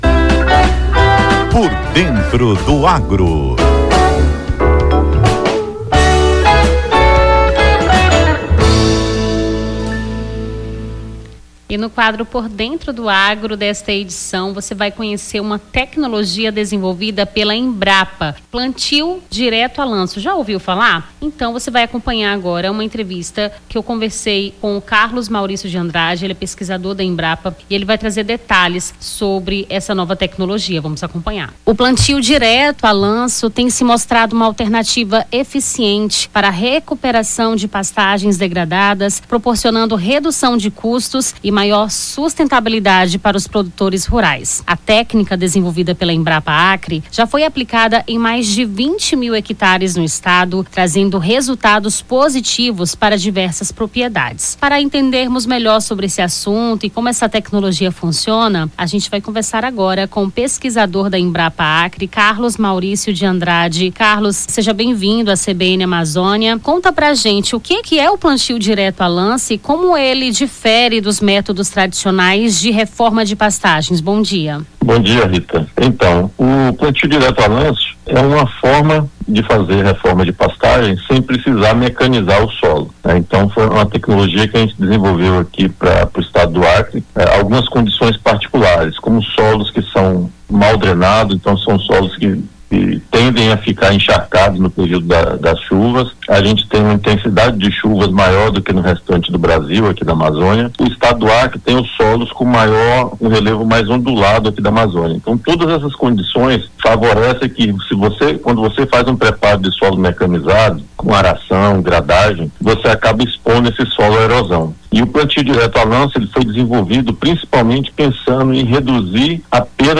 Pesquisador da Embrapa Acre explica como o plantio direto tem se mostrado uma alternativa eficiente para a recuperação de pastagens degradadas - CBN Amazônia